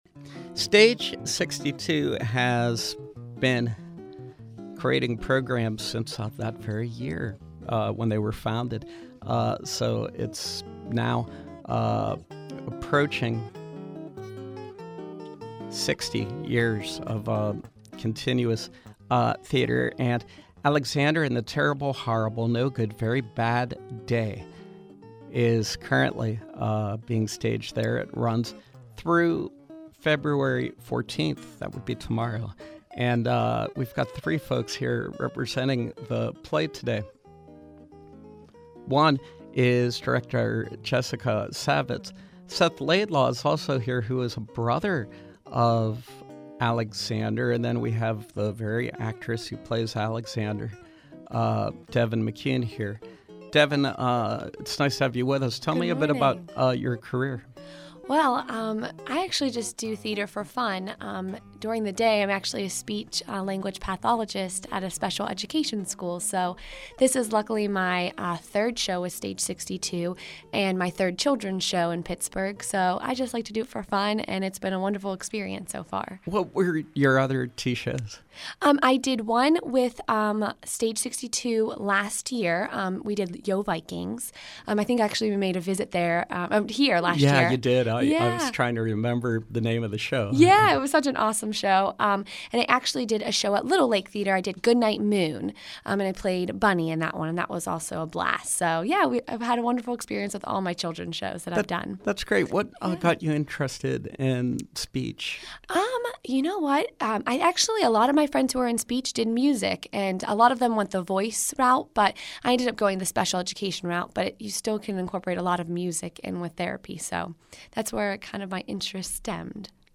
Interview: Alexander and the Terrible, Horrible, No Good, Very Bad Day